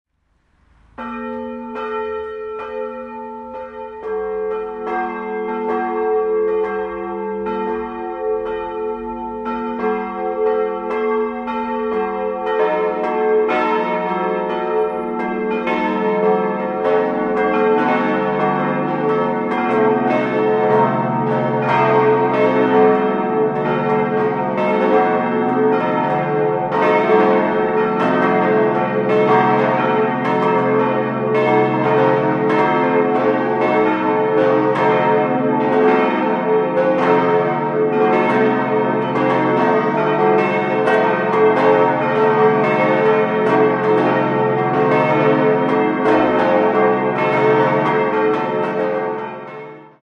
Verminderter Fünfklang: a°-c'-es'-ges'-a'